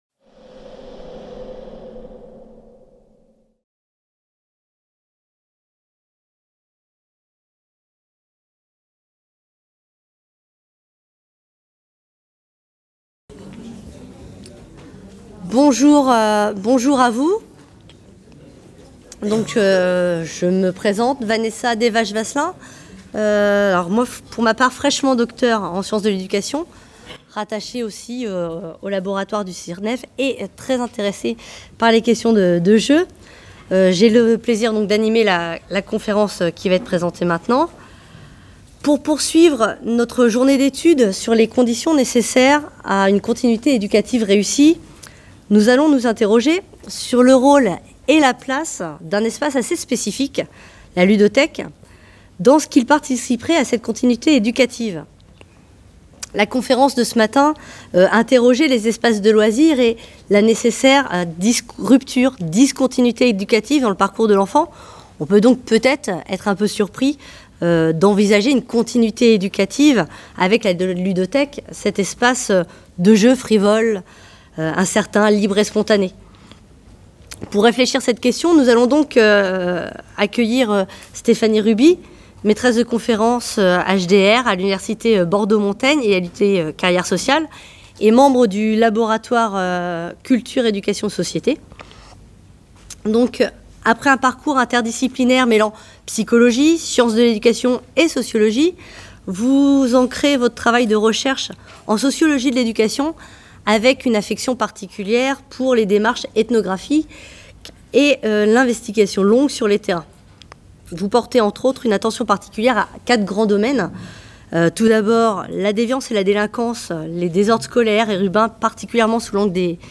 CIRNEF18 | 05 - Conférence 2: Frères et sœurs en jeux. Les ludothèques, partenaires éducatifs des parents | Canal U